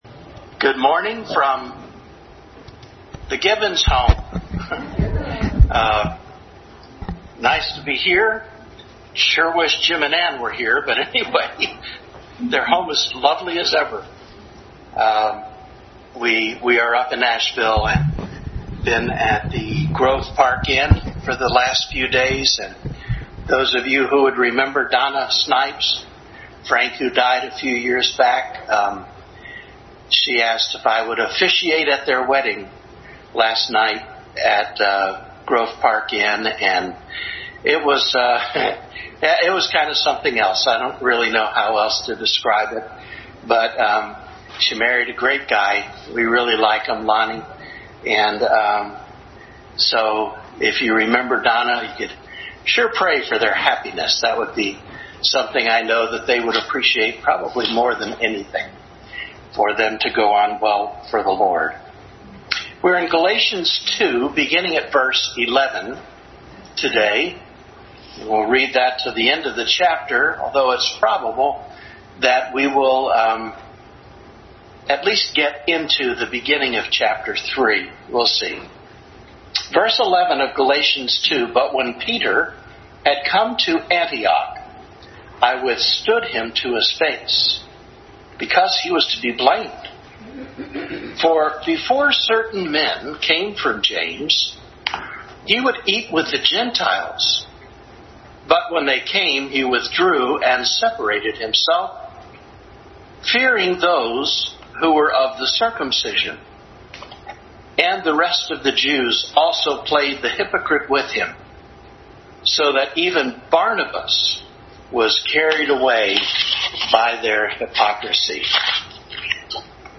Adult Sunday School Class continued study in Galatians.